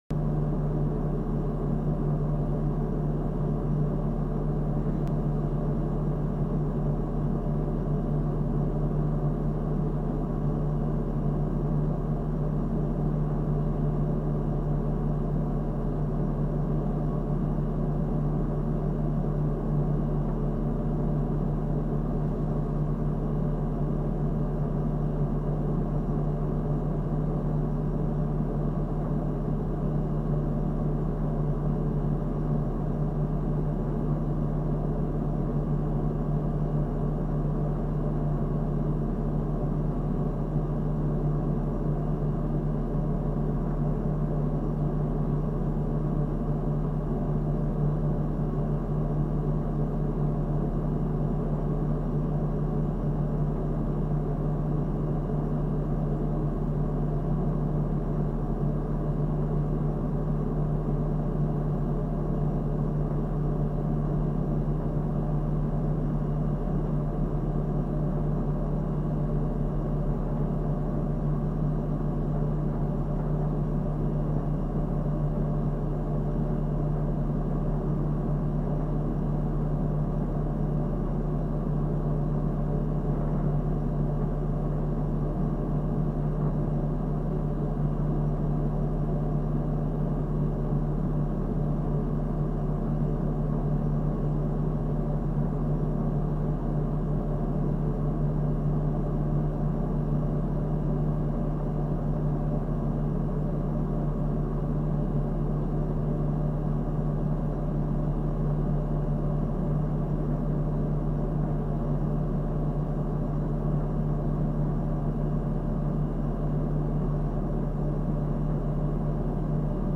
SpaceShipAmbiance.mp3